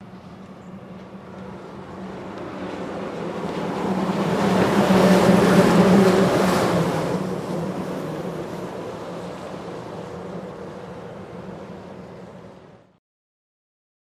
Monorail, Disneyland, Pass By